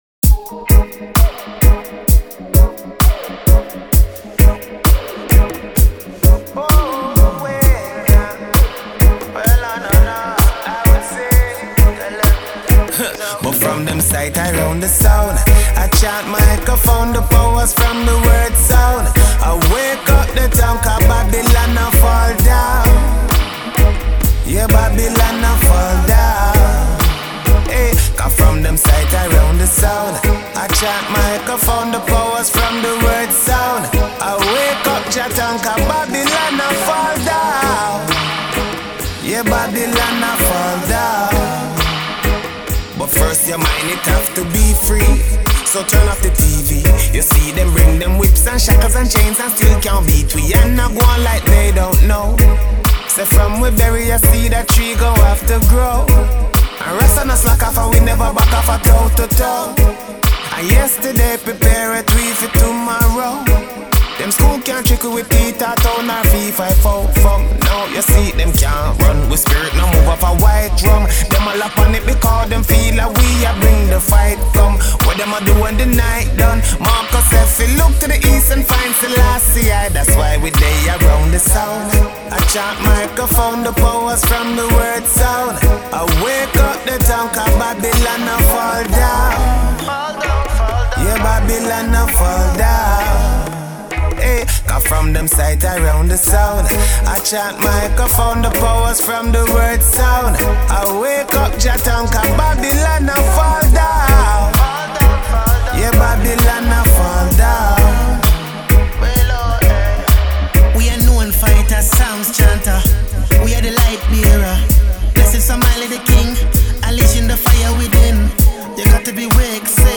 talented lyricist and singer with a serene voice